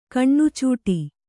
♪ kaṇṇucūṭi